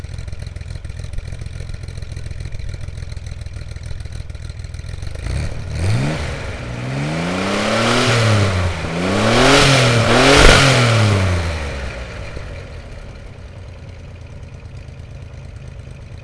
This assembly, pictured below, provided the much less restrictive flow of a dual system without the horrible drone.
Here’s a link to a sound (.wav) file for a setup much like the one pictured, except it uses a Raptor turbo muffler.
66newFlowTechRaptor.wav